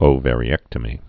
(ō-vârē-ĕktə-mē)